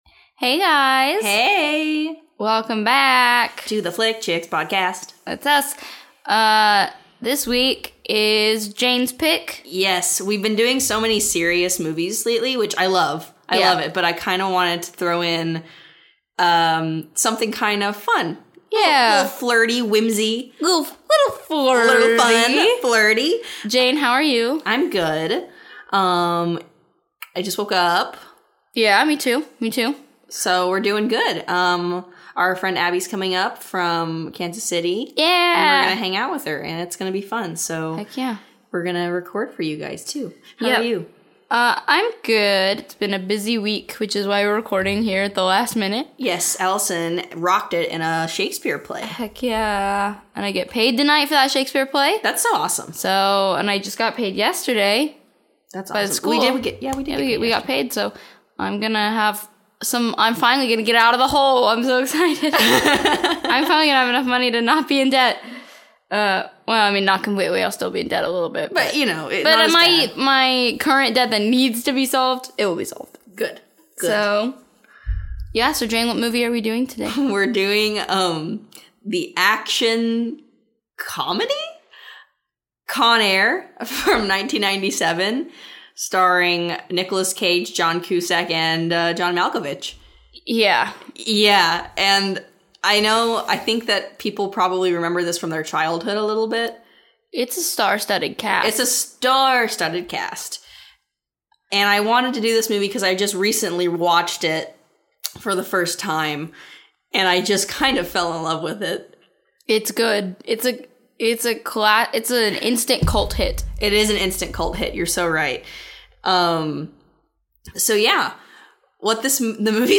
Note: The volume changes a few times during this episode as we readjusted the sound a couple of times.